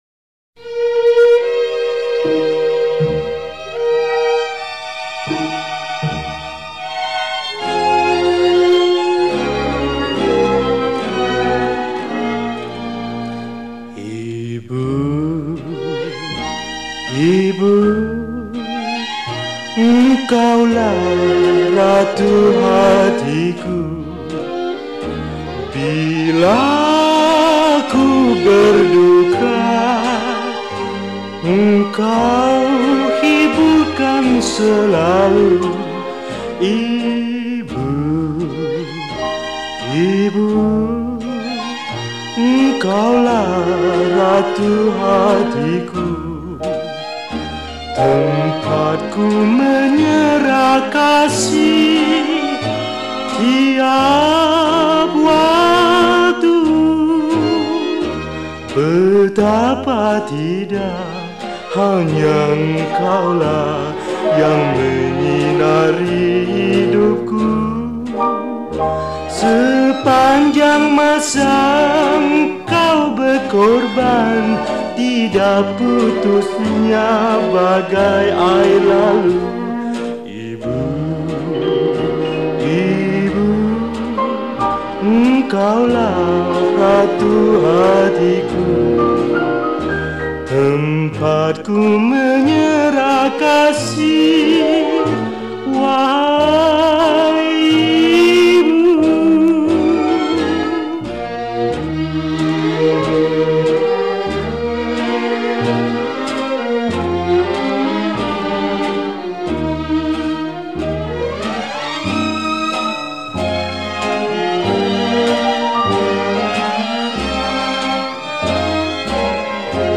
Malay Song
Solo Recorder